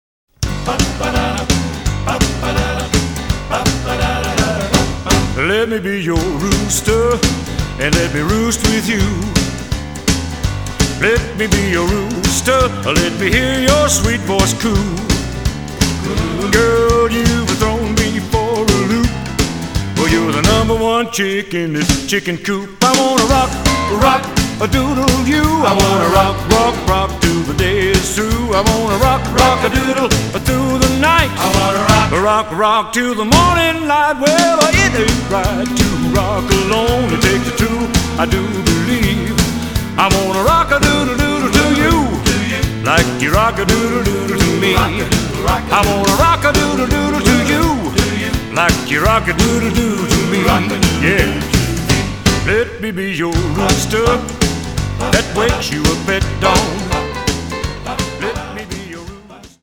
exciting, classic symphonic adventure score